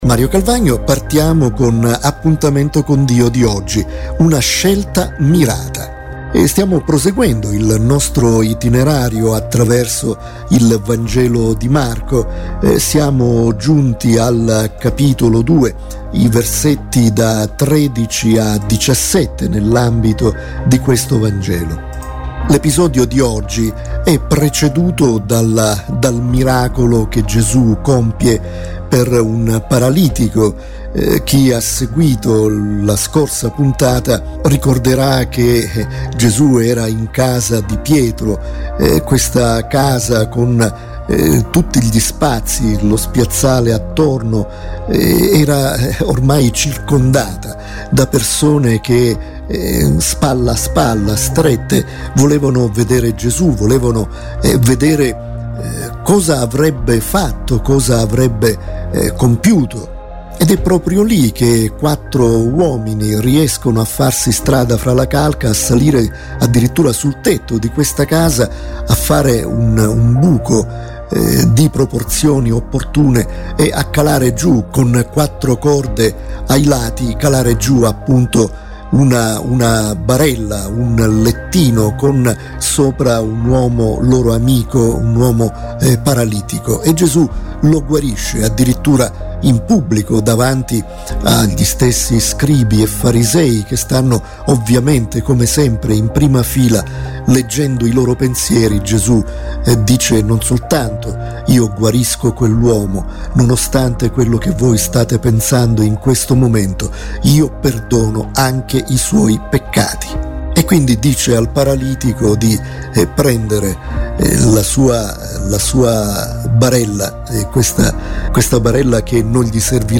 Riflessione spirituale